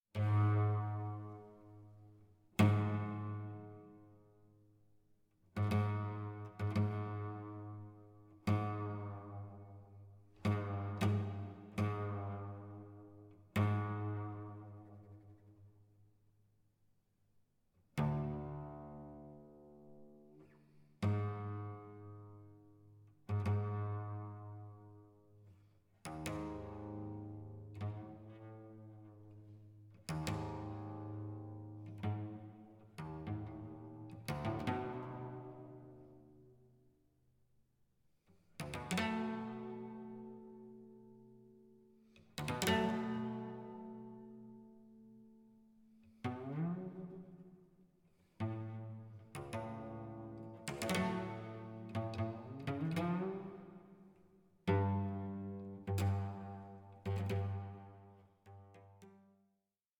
Concerto for Violoncello and Orchestra
In a live performance